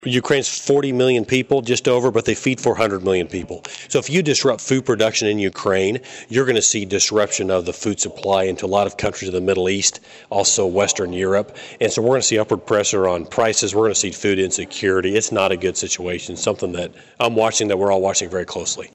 During a visit with local constituents at the Lyon County History Center Mann addressed a number of both foreign and domestic topics.